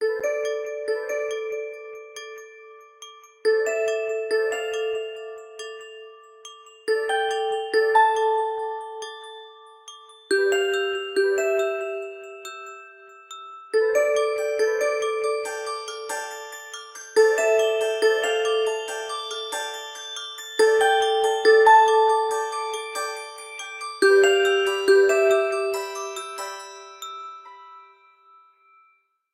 • Качество: 128, Stereo
Стандартный рингтон